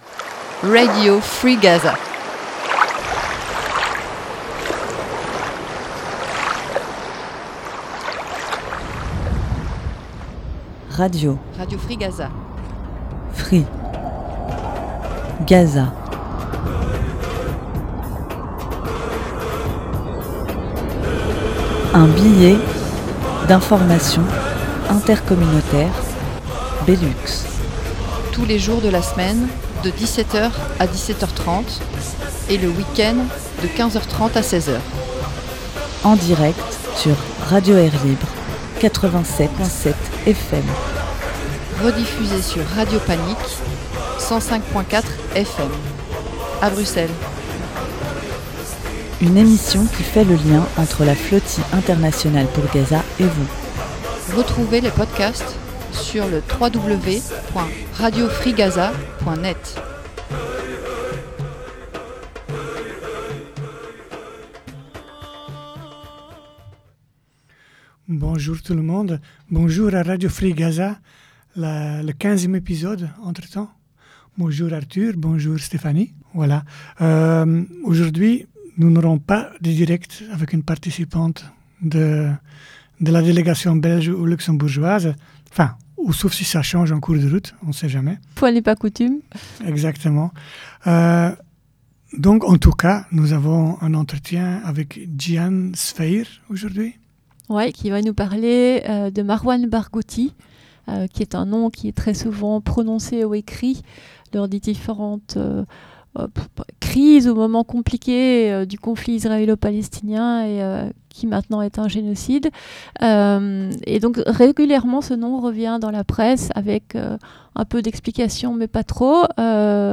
Surprise, pour une fois pas de participant.e en direct.